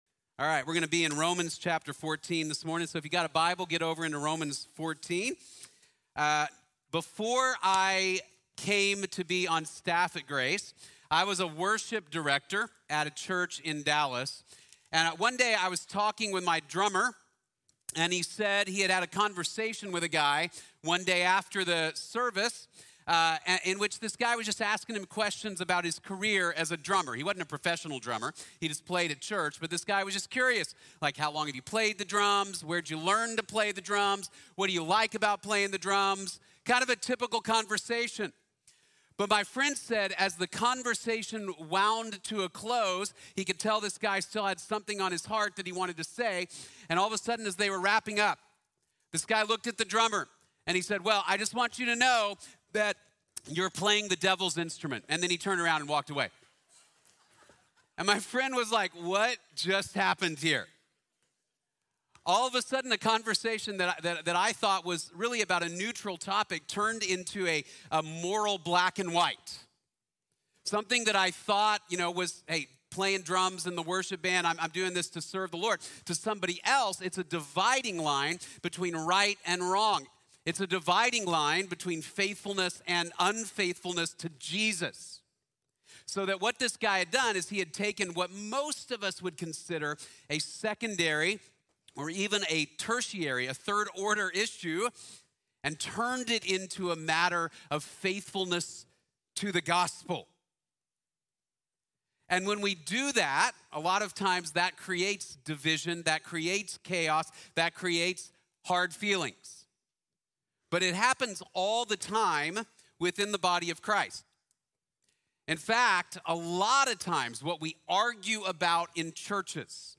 Disagreeing with Love | Sermon | Grace Bible Church